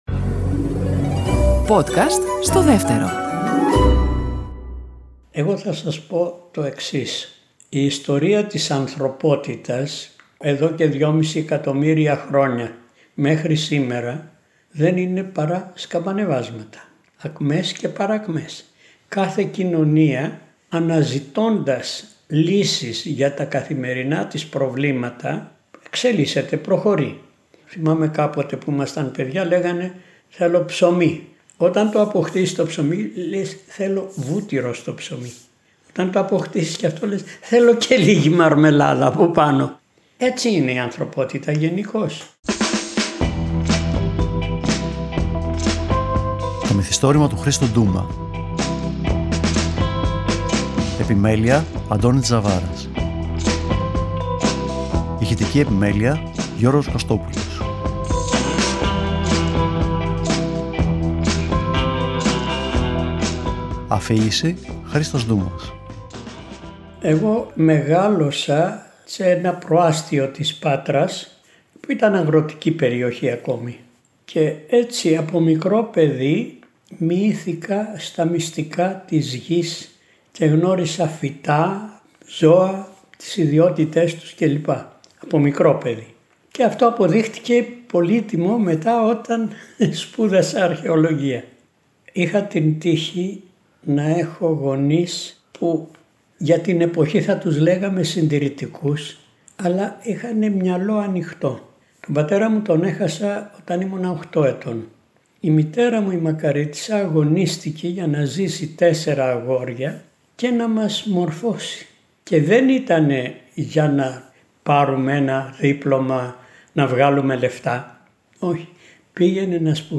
Το αφηγείται με χιούμορ και παρρησία και περιλαμβάνει συναντήσεις με Έλληνες και Ρώσους μεγιστάνες, αντιπαραθέσεις με υπουργούς πολιτισμού, αναμετρήσεις με αρχαιοκάπηλους και -κυρίως- επιμύθια και αλήθειες δικαιωμένες στο πέρασμα του χρόνου, ο οποίος στη δική του περίπτωση μετριέται σε χιλιετίες.